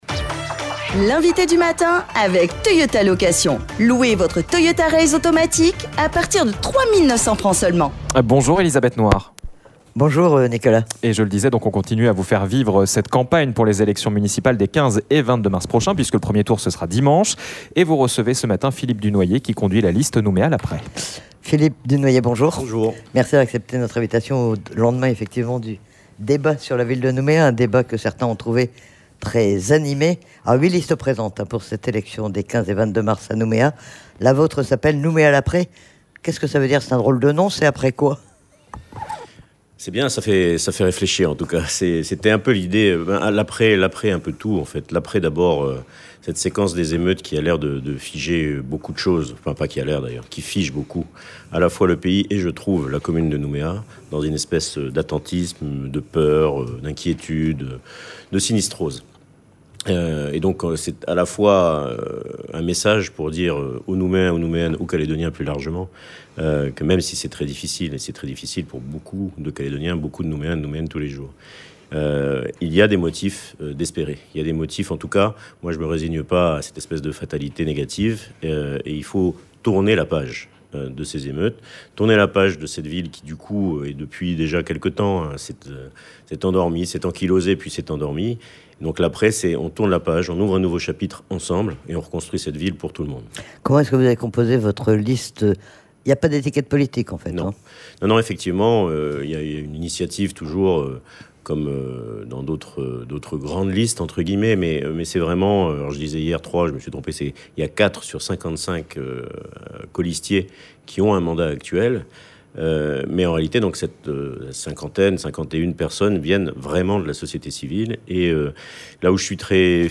Au lendemain du débat sur les élections municipales à Nouméa et à quelques jours du 1er tour, nous recevions Philippe Dunoyer, tête de liste "Nouméa : l'après".